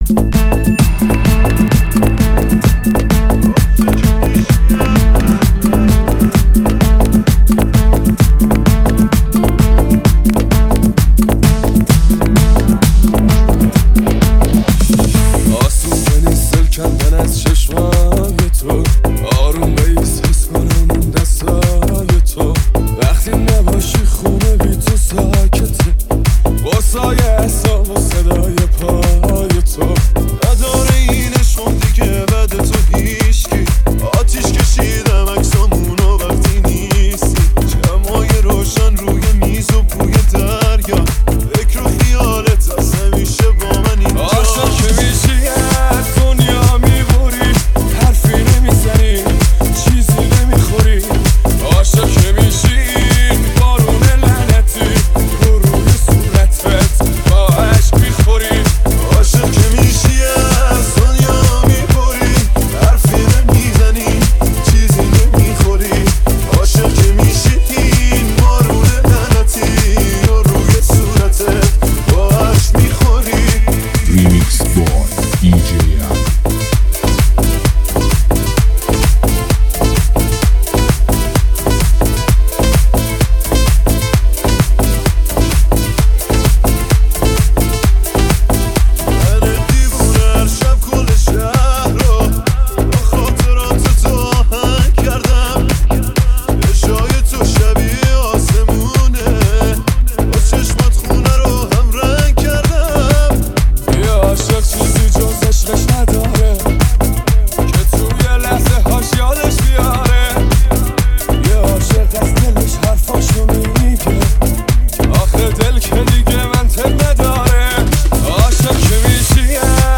موسیقی پرانرژی و شنیدنی برای لحظاتی پر از احساس و شادی.